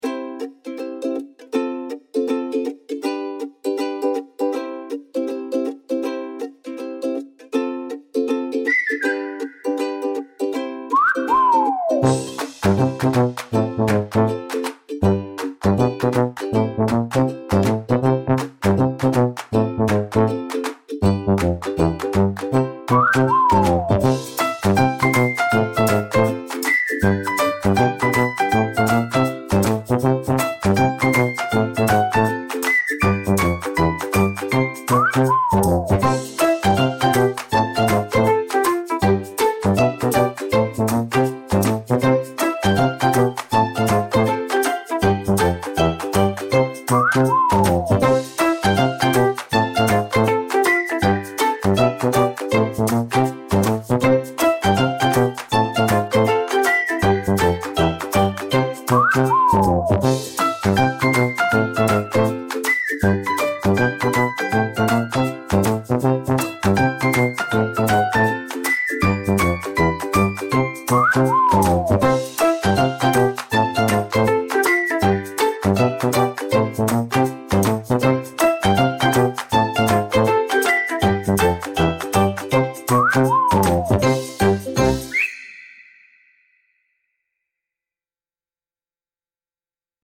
lighthearted bouncy tune with silly sound effects and fast tempo